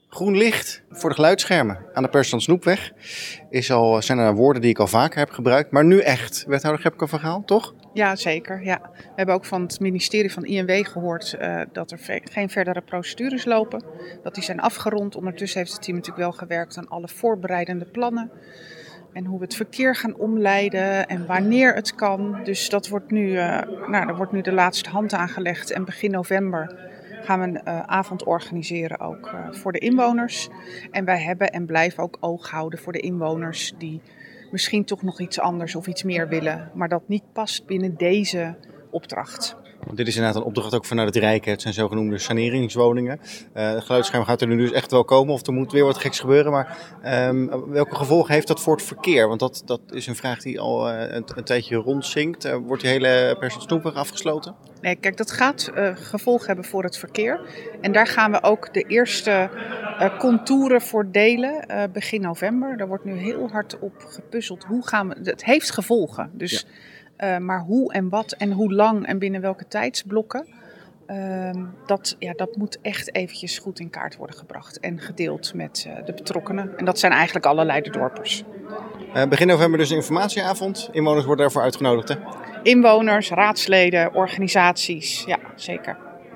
De geplande geluidsschermen langs de Persant Snoepweg in Leiderdorp kunnen definitief worden geplaatst. Dat maakte wethouder Gebke van Gaal maandagavond bekend tijdens de gemeenteraadsvergadering.
Wethouder Gebke van Gaal over geluidsschermen langs de Persant Snoepweg.